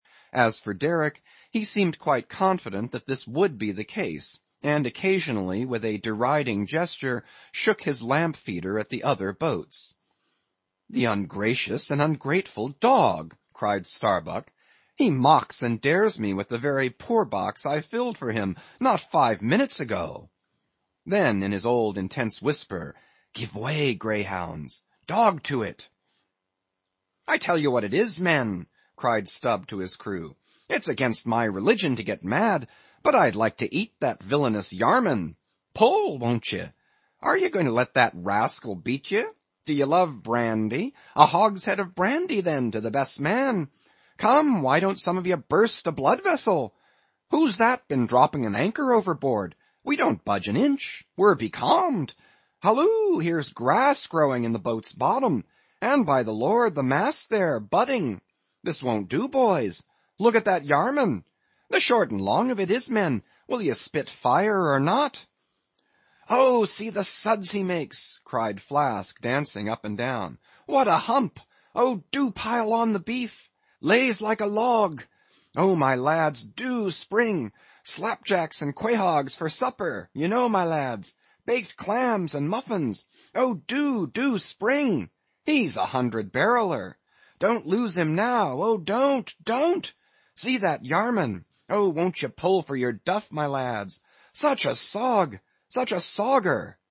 英语听书《白鲸记》第706期 听力文件下载—在线英语听力室